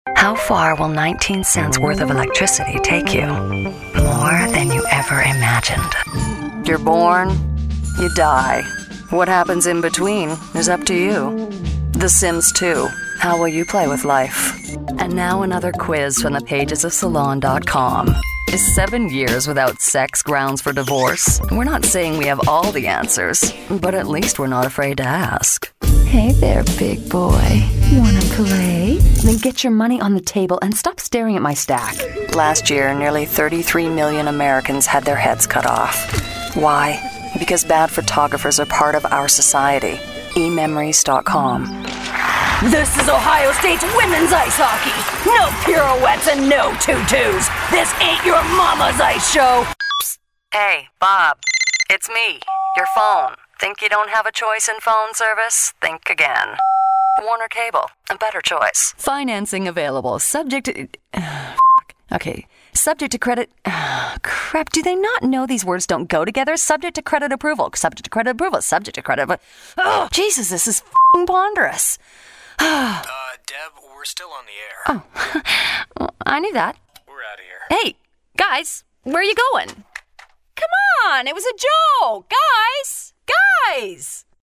Sprecher | Die internationale Sprecheragentur World Wide Voices
Conversational, Energy, Professional, Character, Warm, Experience, English, ISDN